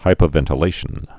(hīpə-vĕntl-āshən)